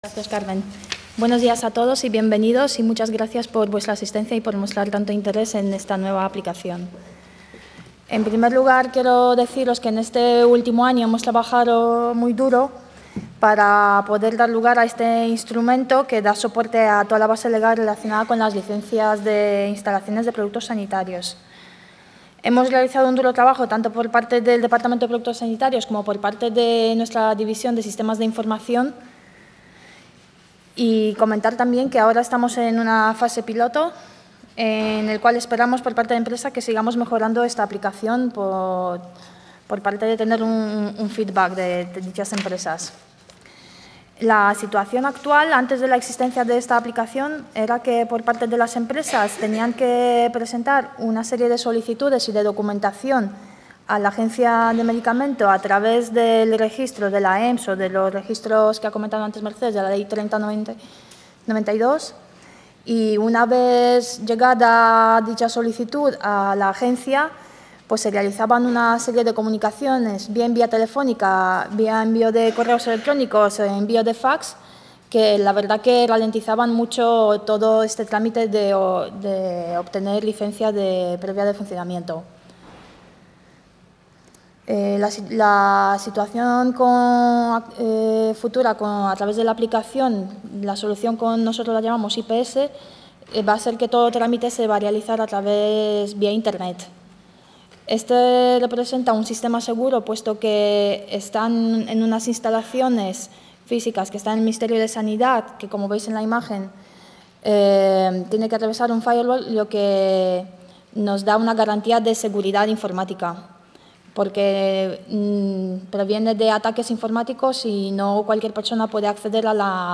Jornada informativa sobre la nueva aplicación para la tramitación electrónica y gestión de las solicitudes de licencia previa de funcionamiento de instalaciones de productos sanitarios
AEMPS Ponencia Archivo de audio (23 min. 49 seg.)